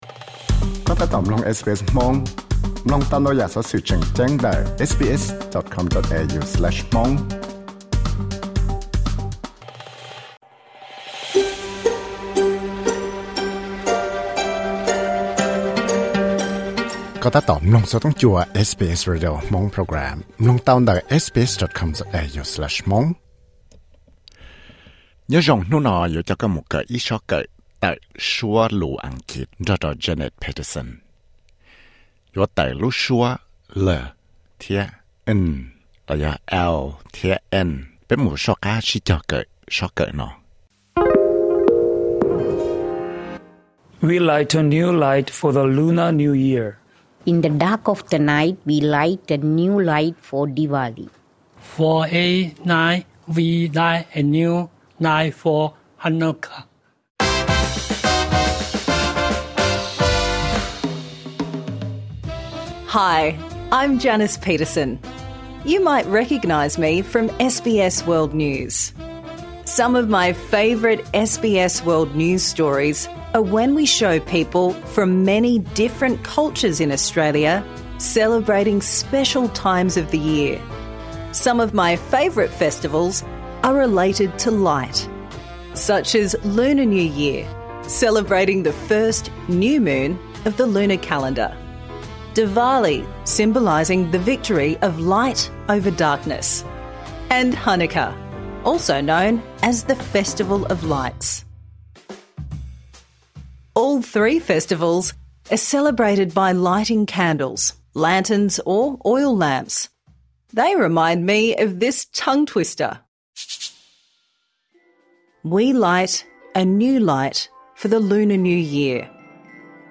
Improve your pronunciation | Season 1